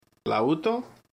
Audio file of the word "Laouto"
Name-of-instrument-LOUTO.mp3